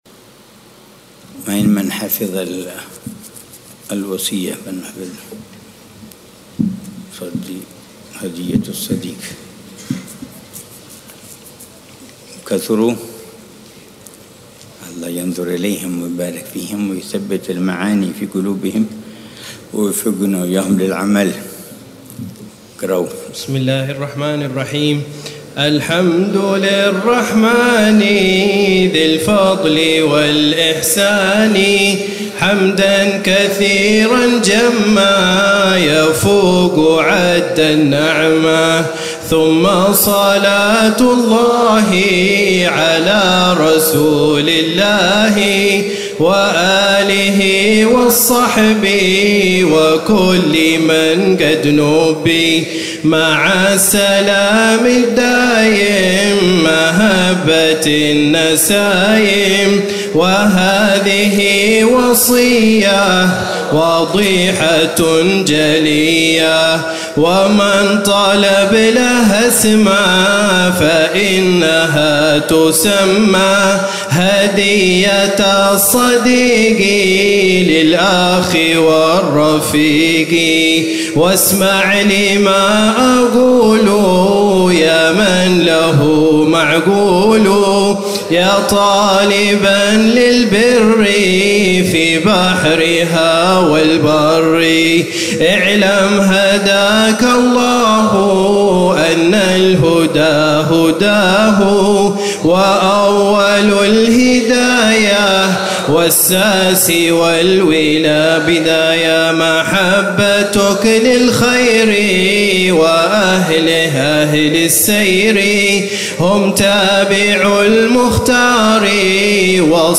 الدرس الرابع (12 محرم 1447هـ)